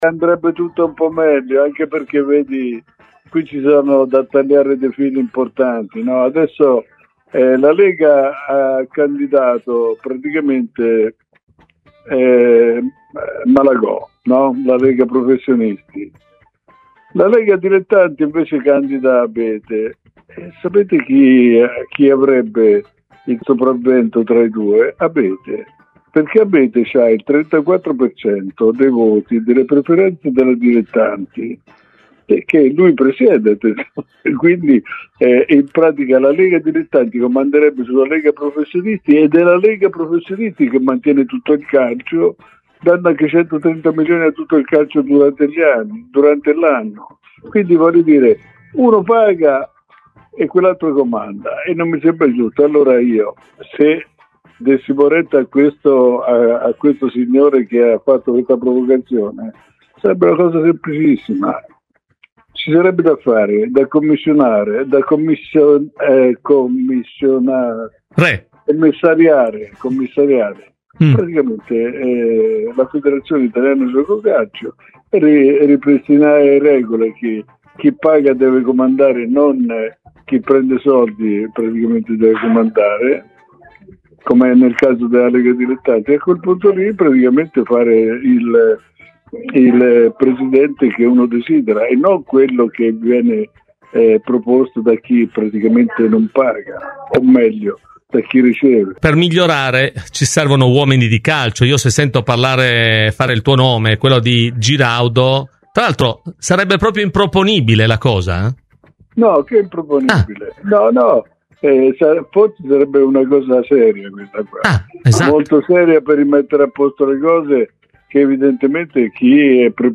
L'ex direttore generale della Juventus Luciano Moggi è intervenuto oggi nel corso di "Cose di Calcio" su Radio Bianconera, per commentare il caos in Federazione, dove dopo l'addio di Gabriele Gravina a giugno si sceglierà il nuovo presidente: “Io dico che il commissariamento sarebbe la cosa migliore se davvero si vogliono rimettere a posto le cose, visto che chi è preposto adesso non è in grado di farlo.